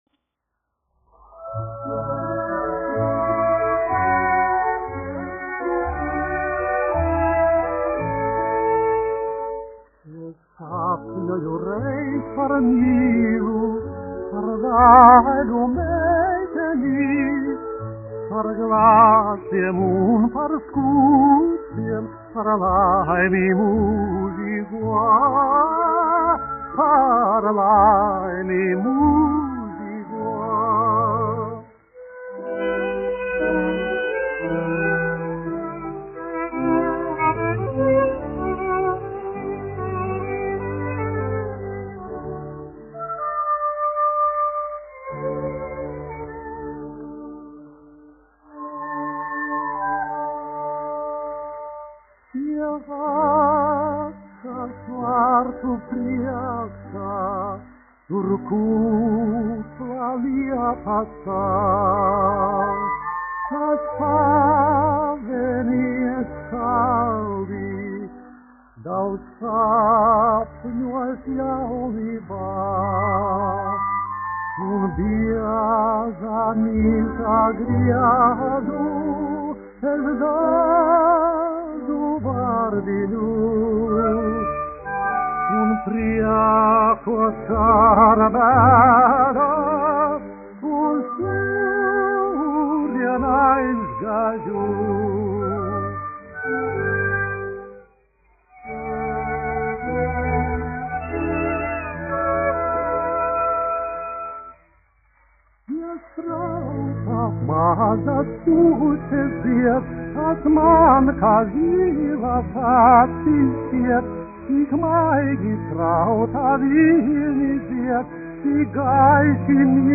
1 skpl. : analogs, 78 apgr/min, mono ; 25 cm
Popuriji
Dziesmas (augsta balss) ar orķestri